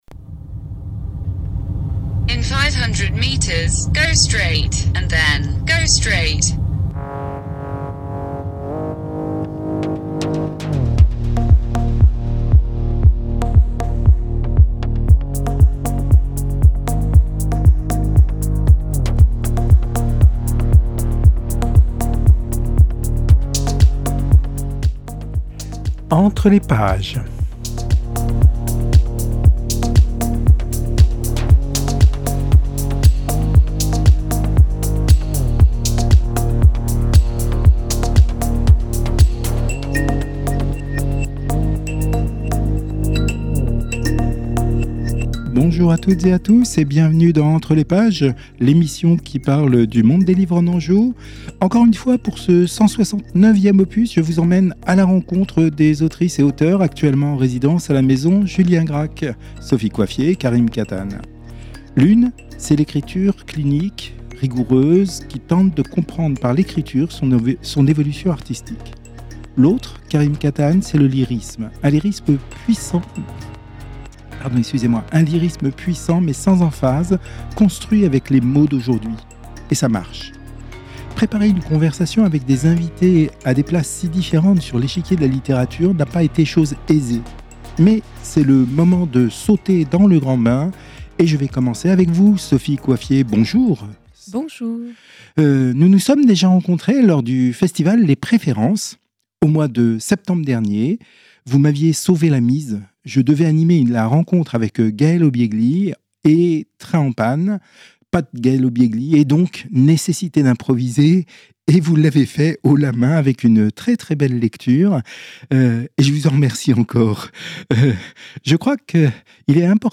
ENTRE LES PAGES, c’est une heure consacrée à l’univers des livres en Anjou. Interviews, reportages, enquêtes, sont au menu.